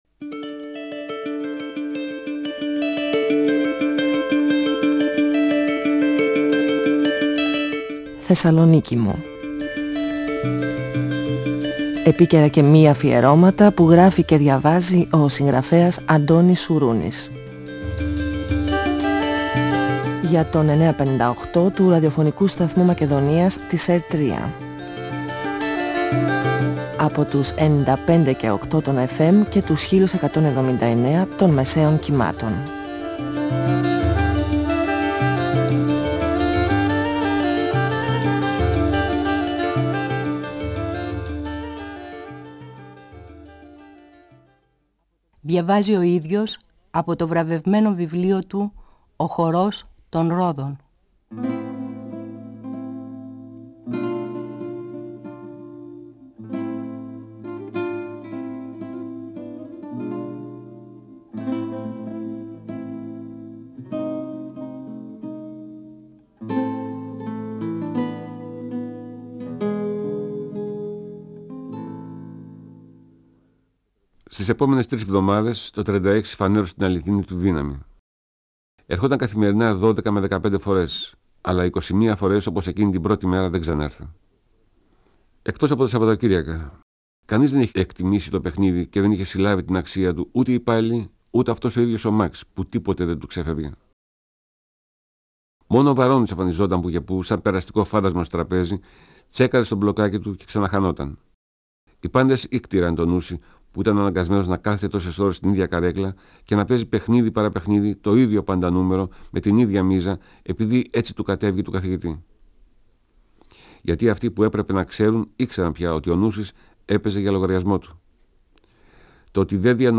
Ο συγγραφέας Αντώνης Σουρούνης (1942-2016) διαβάζει το πρώτο κεφάλαιο από το βιβλίο του «Ο χορός των ρόδων», εκδ. Καστανιώτη, 1994. Ο Νούσης συνεχίζει να ποντάρει στο καζίνο στο νούμερο 36, αυτό που αποφάσισε ο Καθηγητής. Οι παίχτες του Σαββατοκύριακου ακολουθούν τον Νούση, φωνάζοντας κάθε φορά που κερδίζουν.